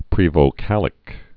(prēvō-kălĭk)